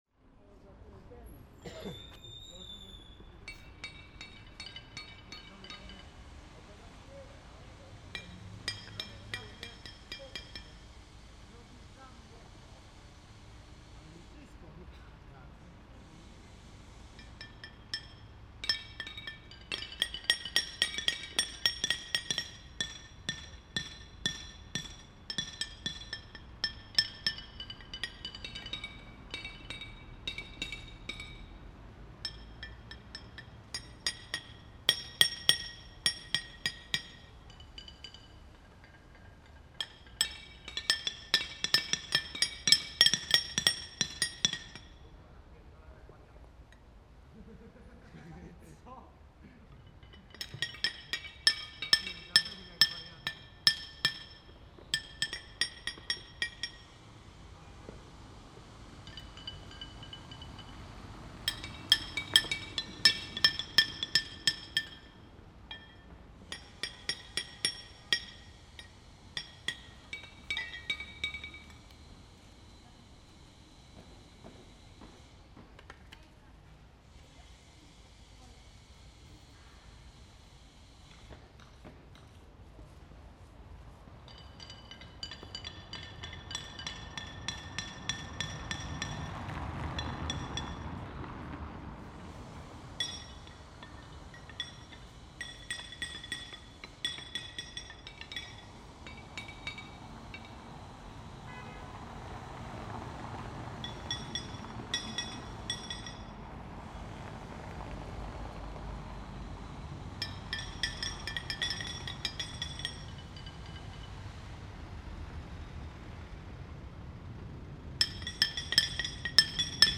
improvised and contemporary music